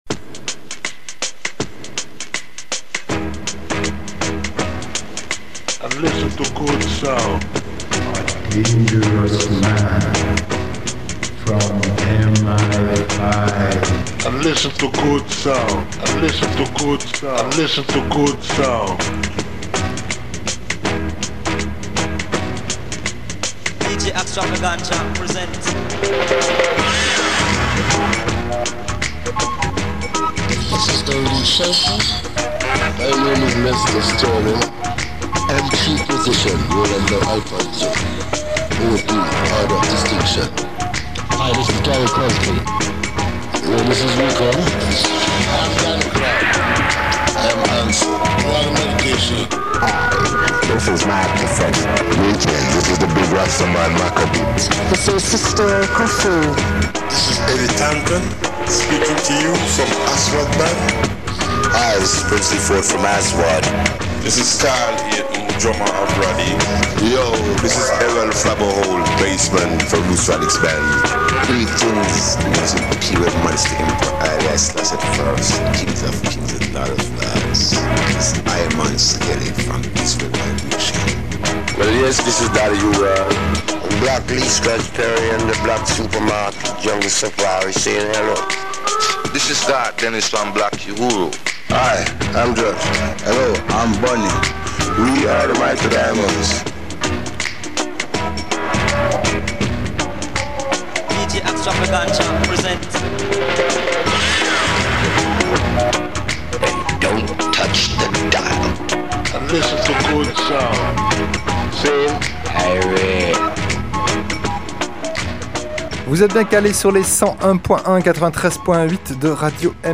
radio show
(ska rocksteady)